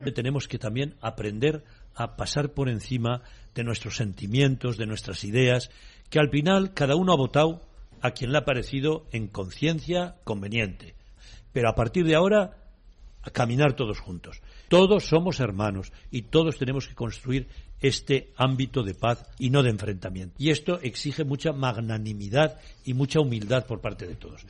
"Venimos de una situación muy tensa", ha afirmado hoy el arzobispo en una entrevista en el programa 'Converses' de COPE-Catalunya, por lo que, según ha añadido, "todo lo que sea ayudar a avanzar en la convivencia será lo mejor".